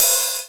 VEC3 Ride
VEC3 Cymbals Ride 11.wav